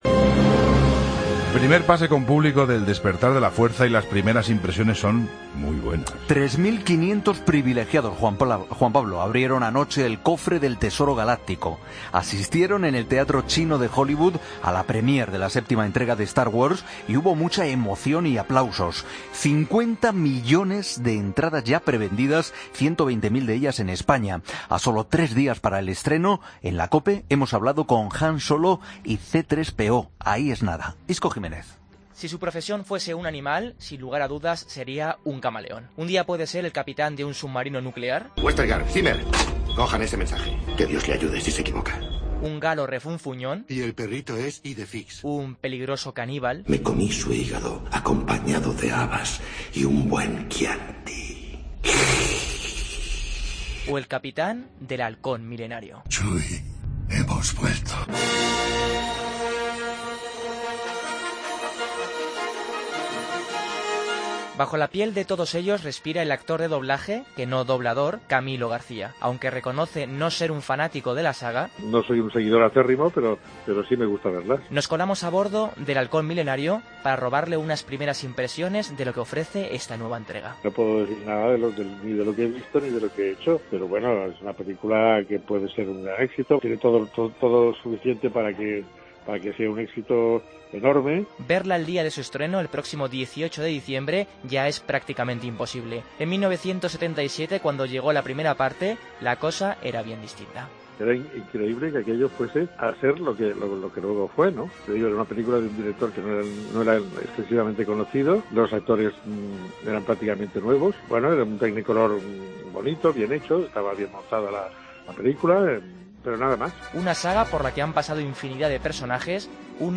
3.500 espectadores han podido acudir al estreno mundial de "El despertar de la fuerza". Hablamos con algunos de sus personajes más famosos, o con los actores de doblaje que los interpretan